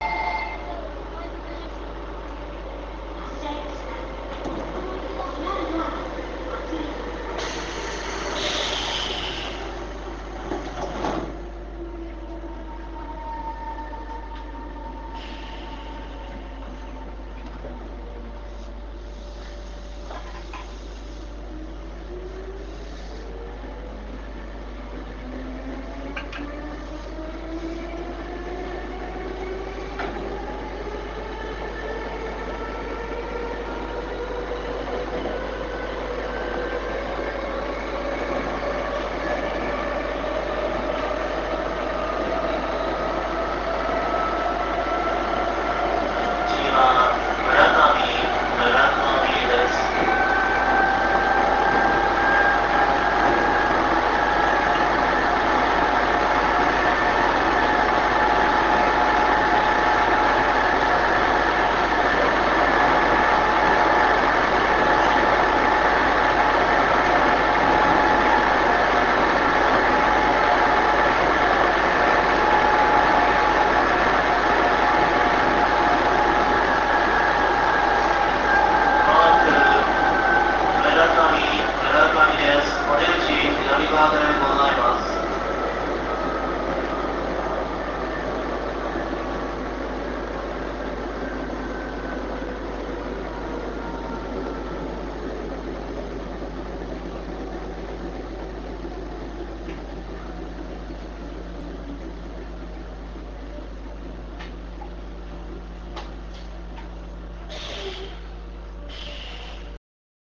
営団５０００系（標準）走行音 八千代中央→村上 RealAudio形式 225kb
５０００系の走行音です。